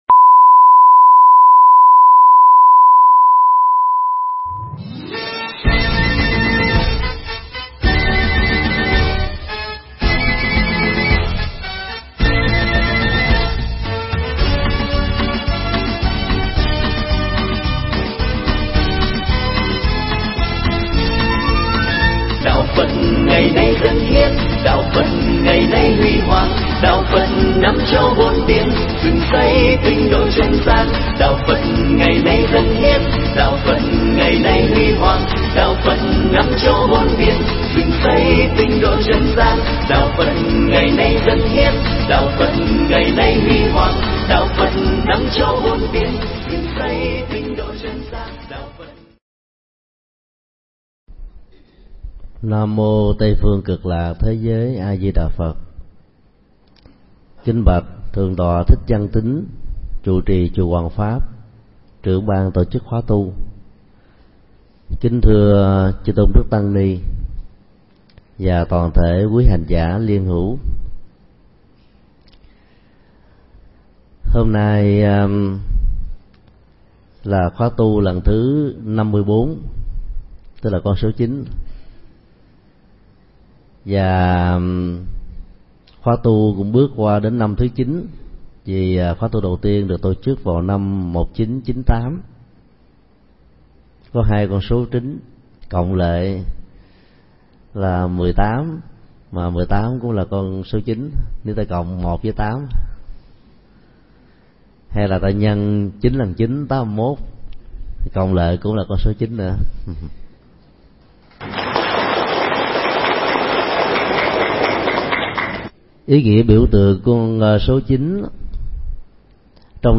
Mp3 Pháp thoại Kinh niệm Phật ba la mật 5: Phương pháp quán tưởng niệm Phật được thầy Thích Nhật Từ giảng tại chùa Hoằng Pháp ngày 08 tháng 12 năm 2008